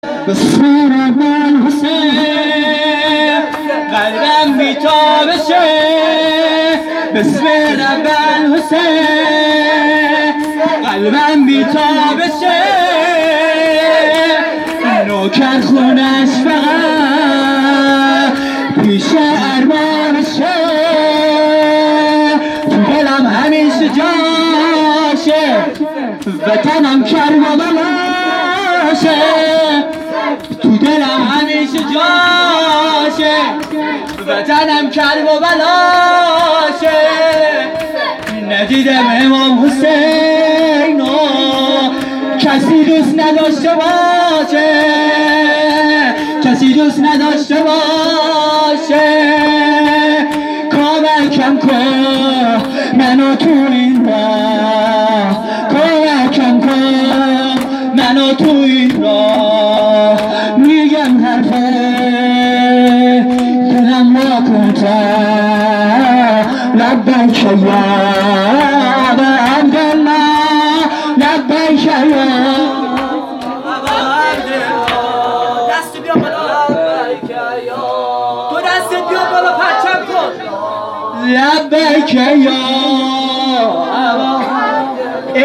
مراسم ایام محرم الحرام